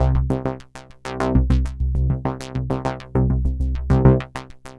tx_synth_100_fatsaws_C1.wav